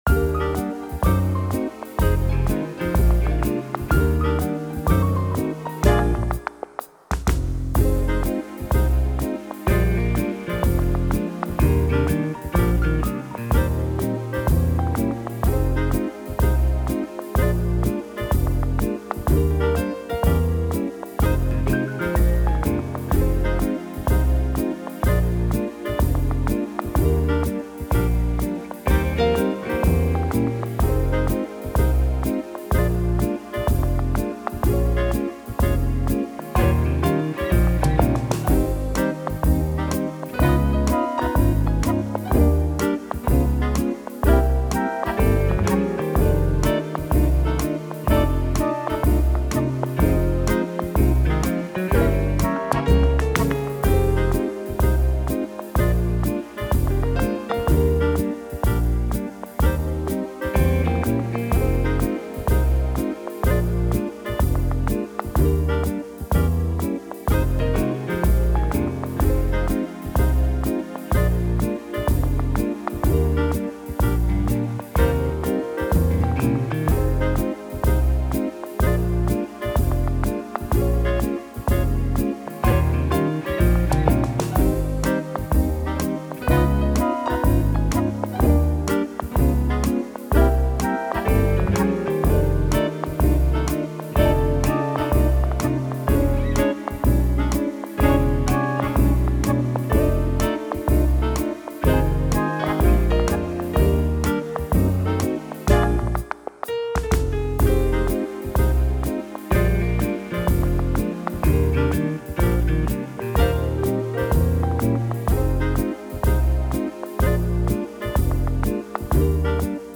Song style: tap